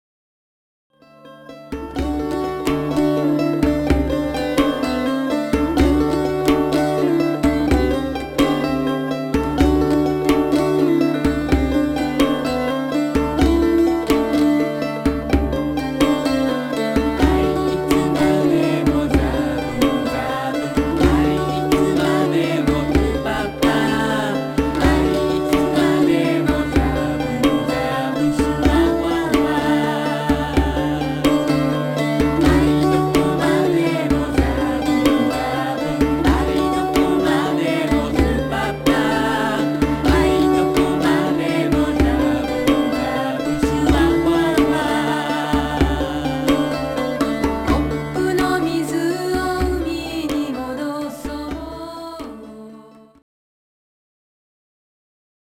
フォルクローレミュージックのバンドを組んでいます。